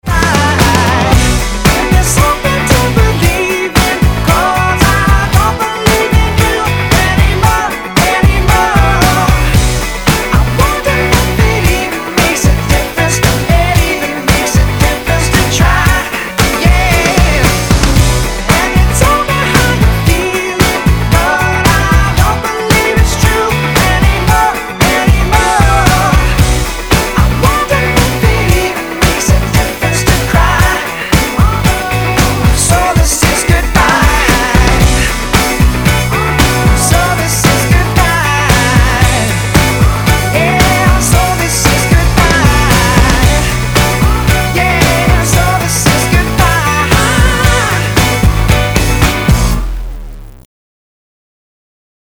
На спектрально плотном материале с задавленной микродинамикой эффект будет не столь показателен, хотя и абсолютно очевиден (приложен А-Б тест: 4 такта оригинал, 4 такта восстановленные с -68 дБ без дитера).